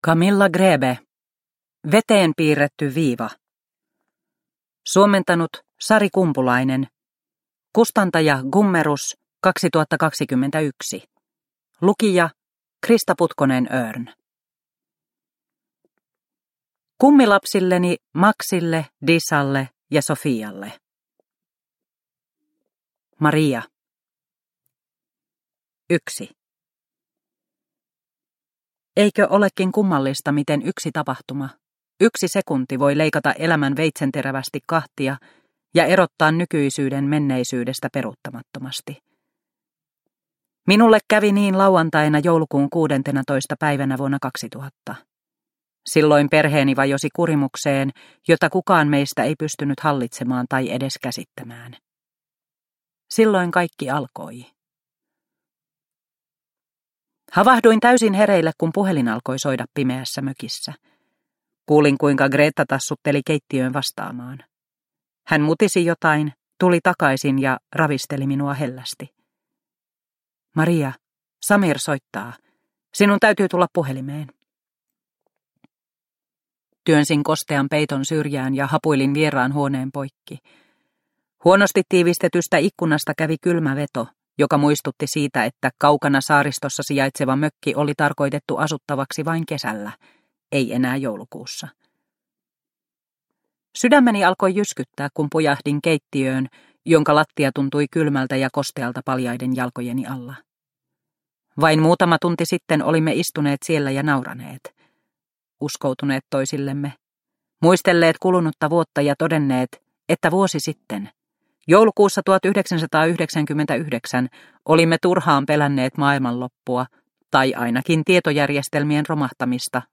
Veteen piirretty viiva – Ljudbok – Laddas ner